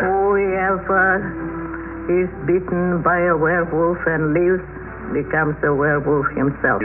here to hear what Gypsy Malvera (Maria Ouspenskaye) has to say.